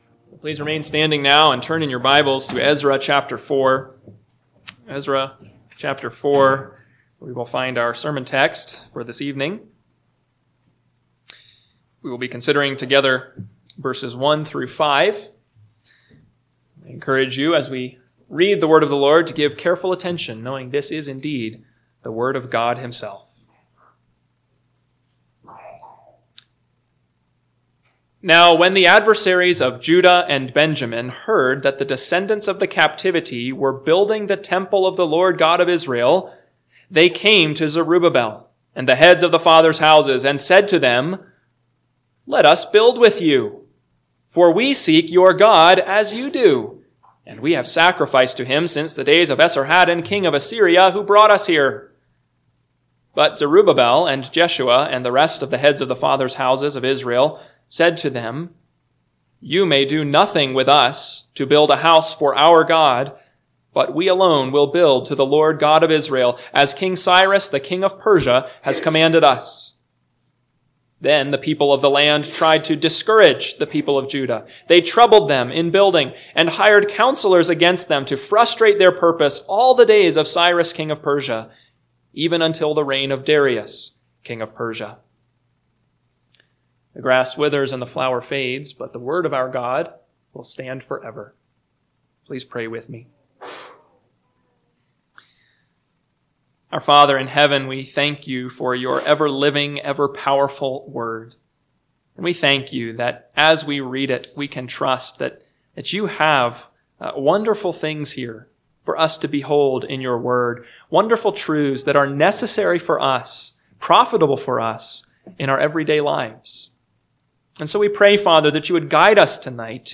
PM Sermon – 3/16/2025 – Ezra 4:1-5 – Northwoods Sermons